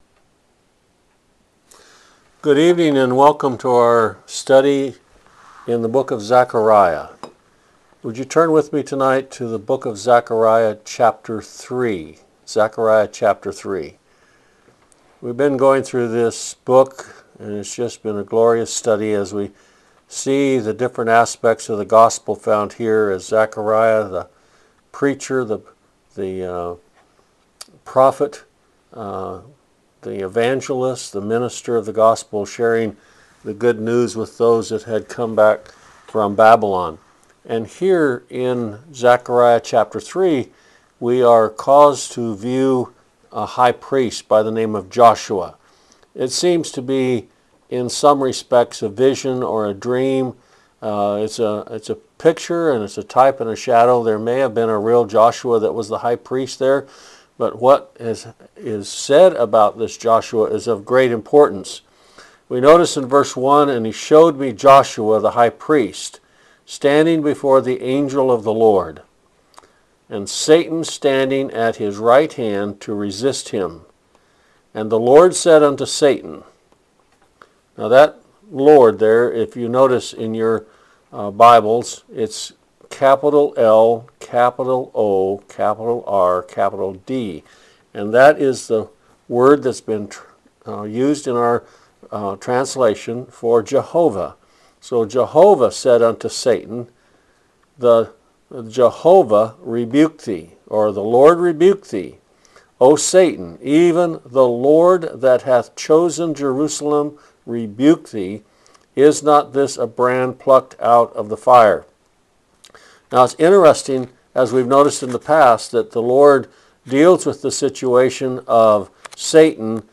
Thine Iniquity Is Passed | SermonAudio Broadcaster is Live View the Live Stream Share this sermon Disabled by adblocker Copy URL Copied!